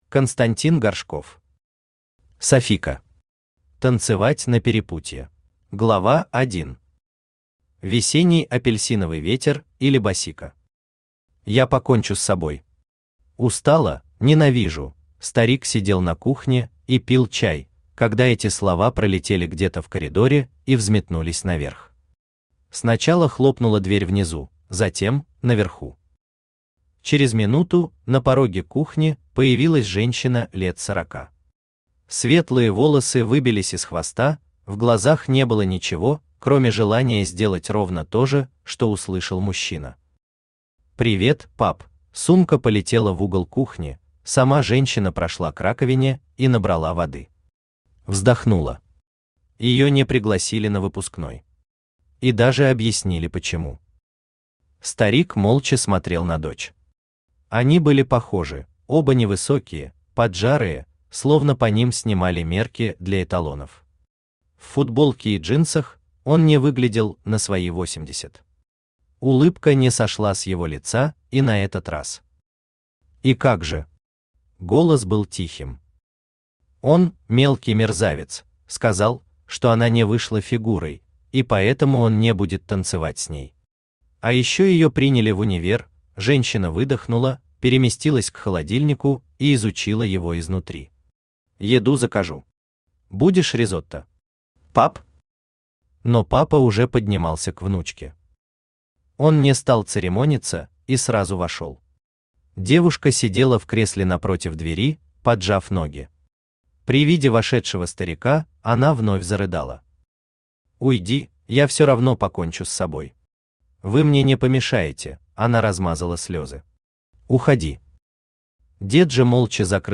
Аудиокнига Sofiko. Танцевать на перепутье